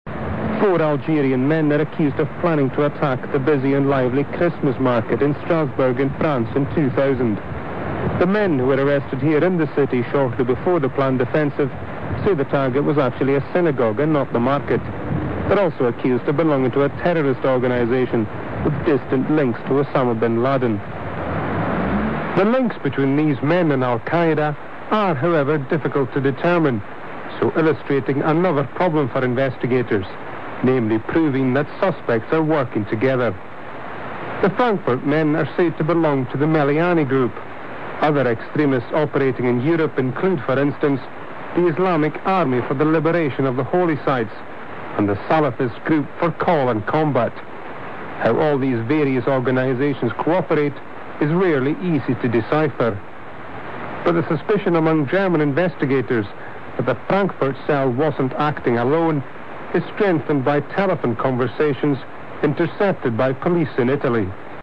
Scotland, general
More moderate accents of Scottish English tend to retain the high mid vowel [ʉ] in the GOOSE lexical set and to be rhotic, i.e. /r/ in syllable-final position is pronounced, e.g. card [kaɻd]. Vowel length and quality tends to be much closer to more mainstream varieties of English.
Scotland_Moderate.wav